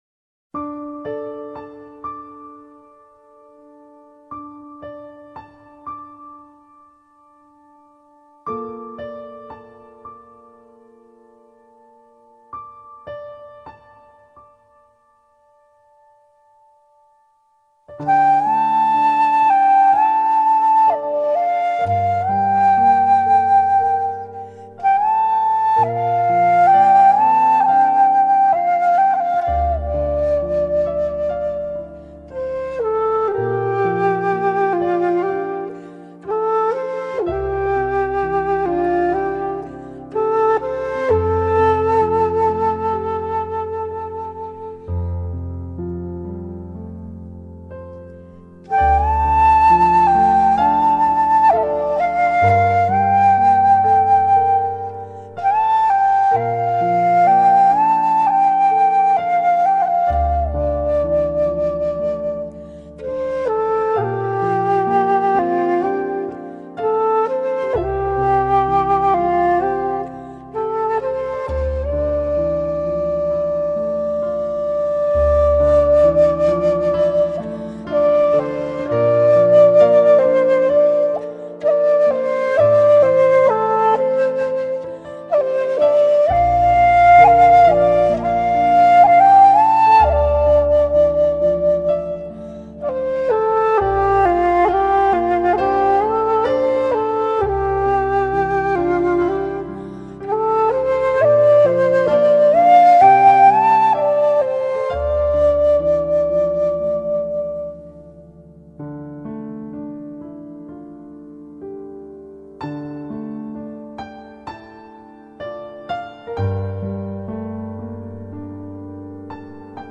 Lately in my spare time I have been making this little toy, my own little Modular Synthesizer.
BambooFlute.mp3